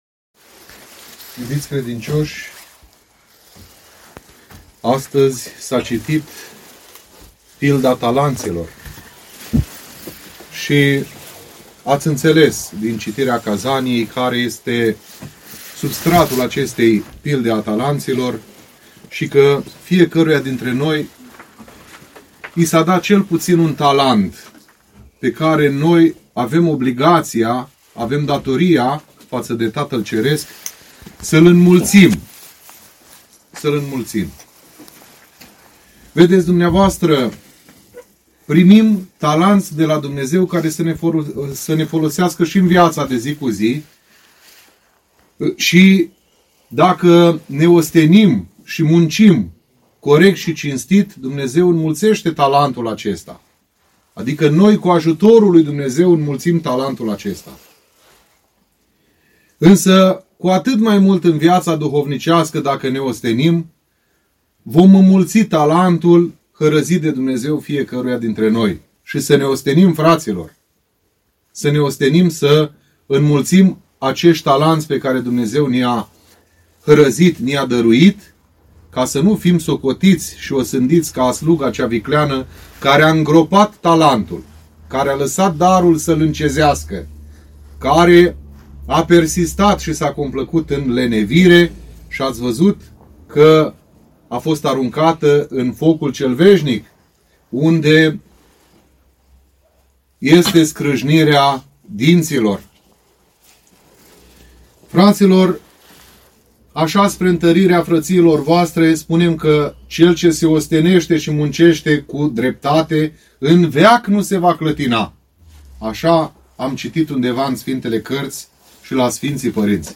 Predica poate fi și descărcată în format audio mp3 de aici: